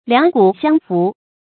两瞽相扶 liǎng gǔ xiāng fú
两瞽相扶发音